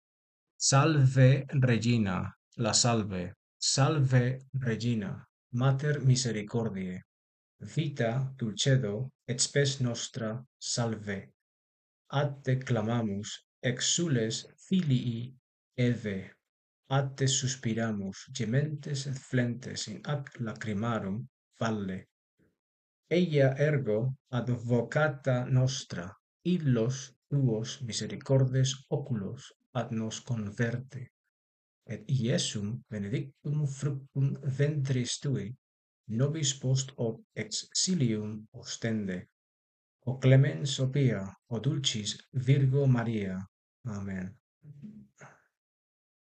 (Descarga el audio de cómo pronunciar la Salve Regina)